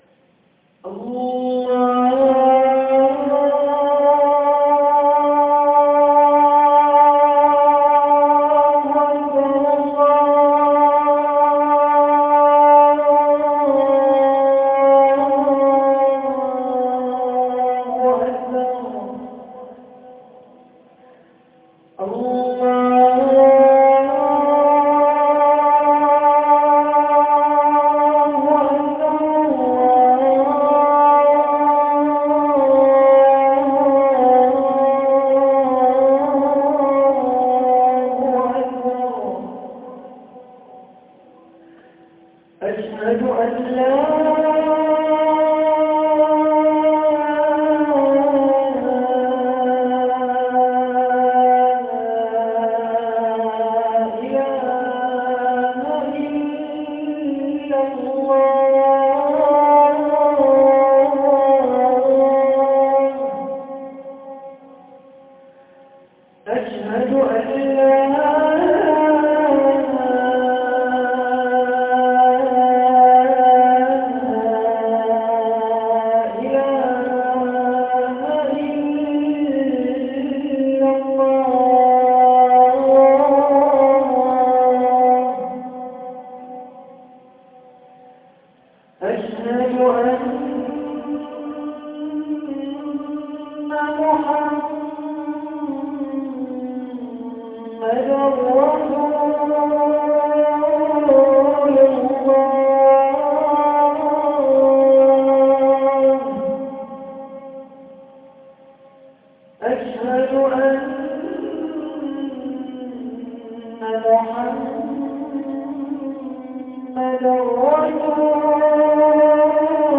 Adhan 38.mp3